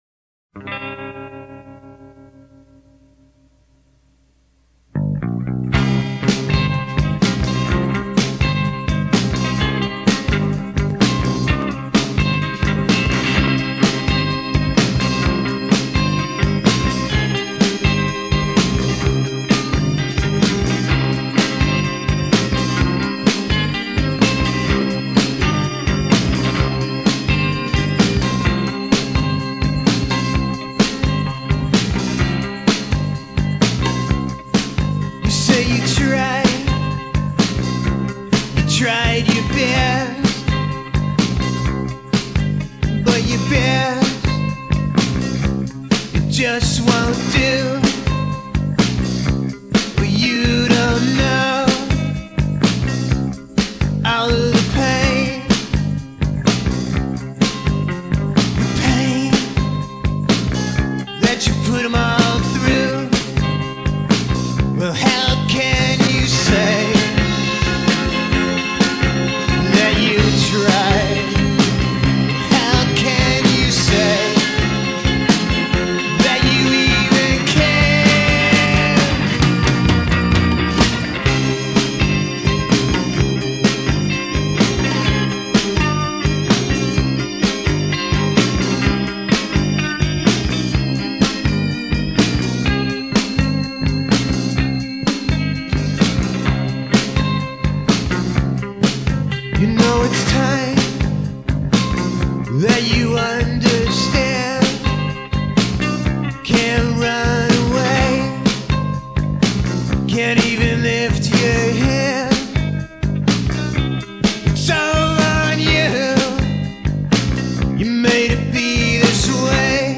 garage band
vocals, organ
guitar
bass
drums.